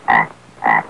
African Frog Sound Effect
african-frog.mp3